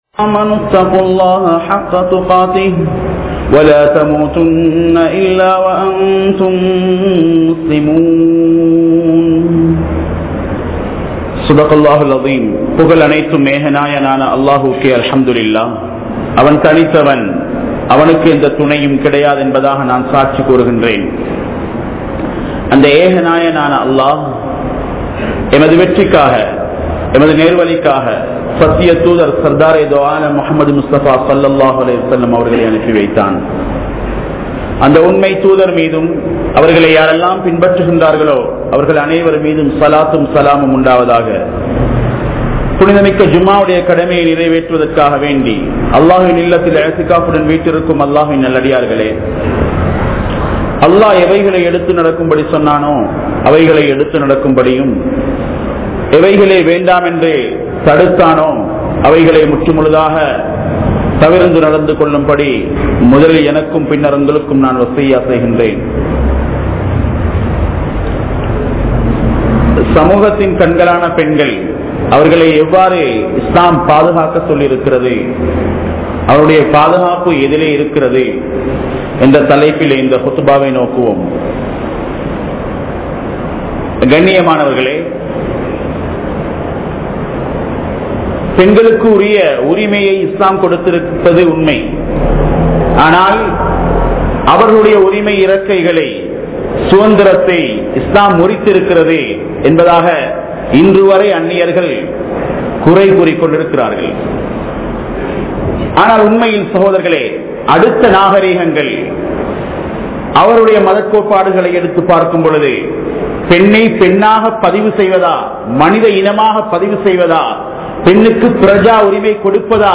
Penmaiyai Ilakkum Indraya Pengal(பெண்மையை இழக்கும் இன்றைய பெண்கள்) | Audio Bayans | All Ceylon Muslim Youth Community | Addalaichenai